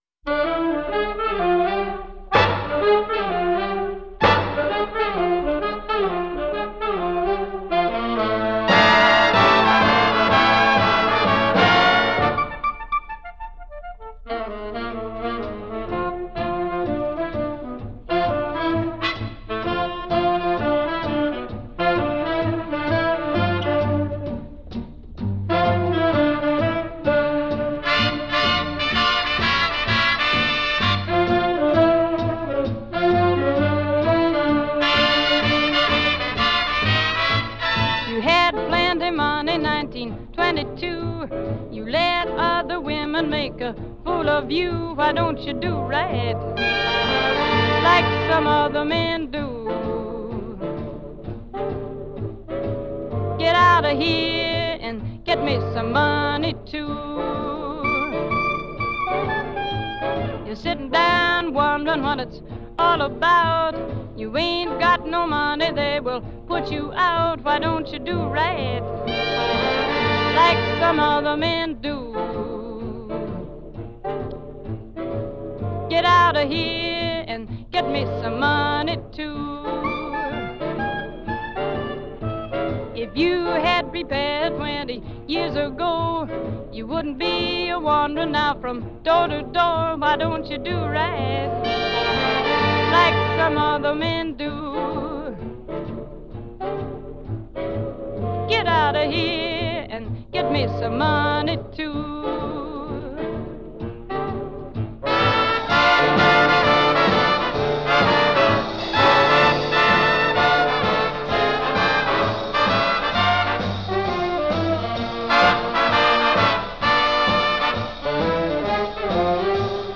1936   Genre: Pop   Artist